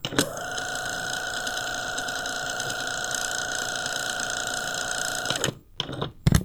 Rebobinar una cinta porta-estudio
cinta de casete